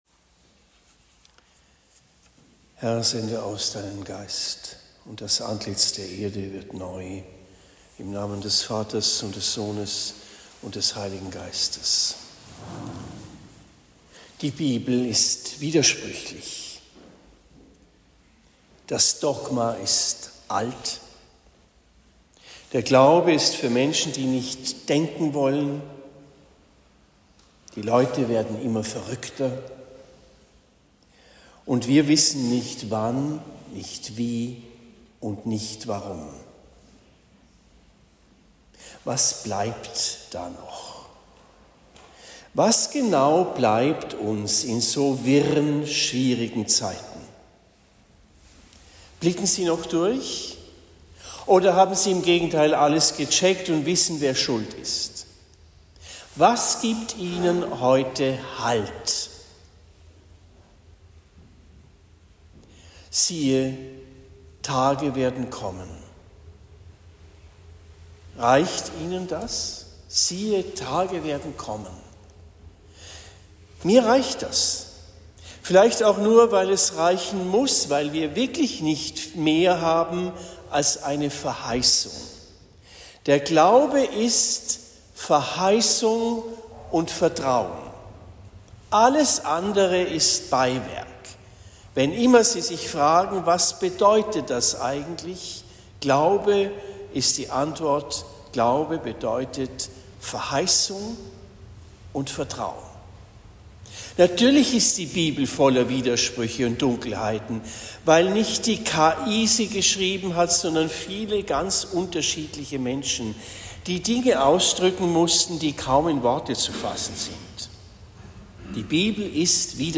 Predigt in Rettersheim am 01. Dezember 2024